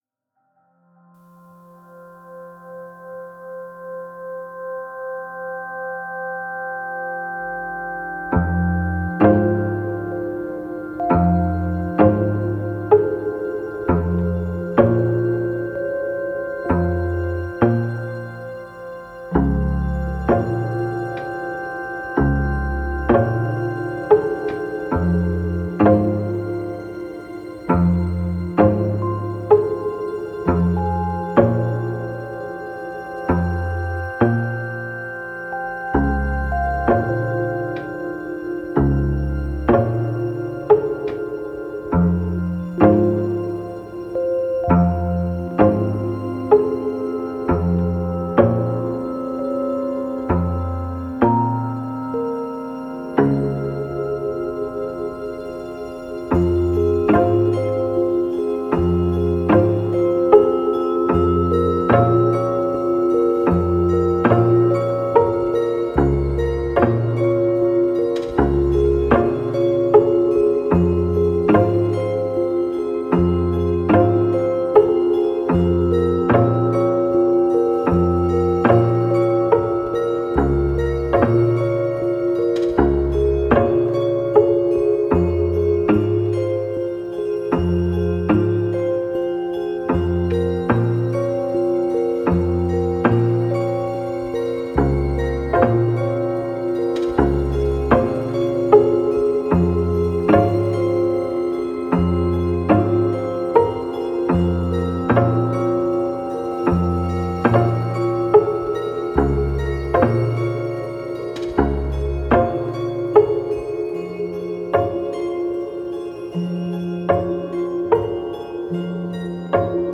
Dreamlike atmosphere of lonely, reflective and sad emotions.